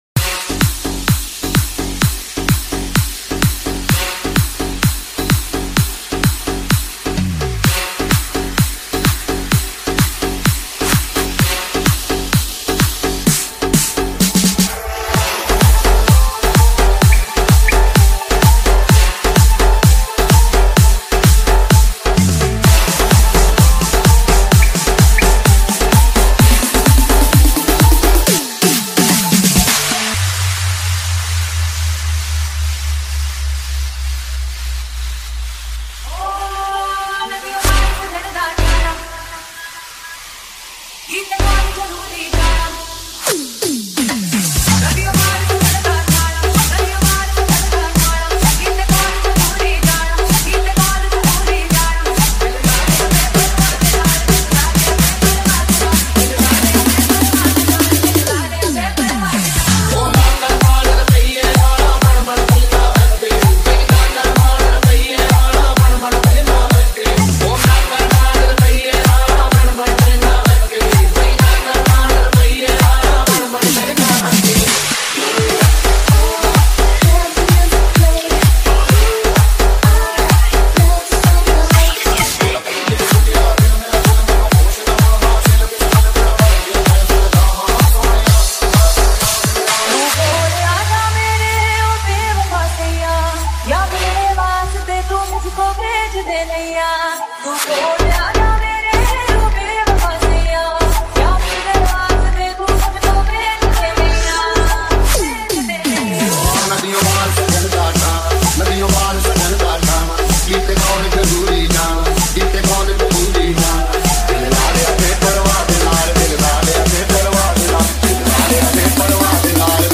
Club Remix